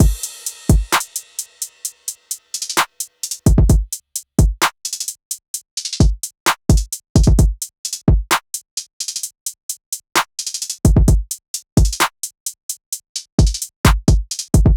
SOUTHSIDE_beat_loop_cheddar_full_02_130.wav